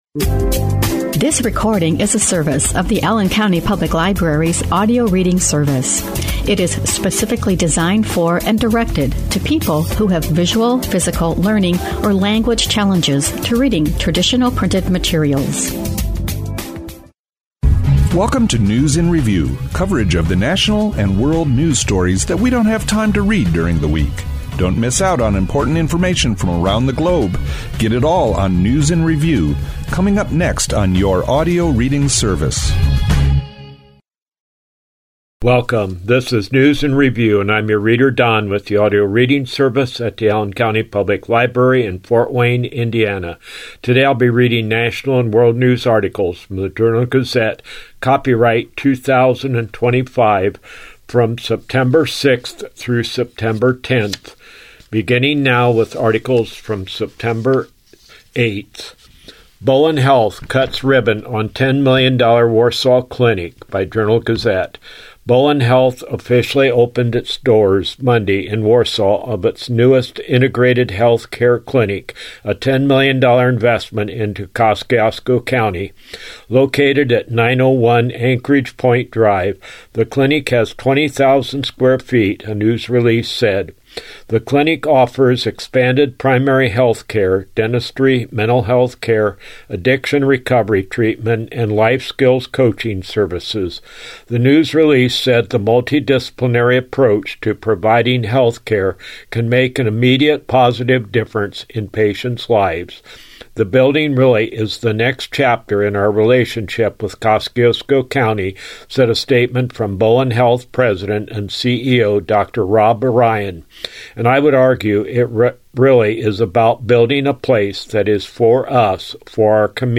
Read aloud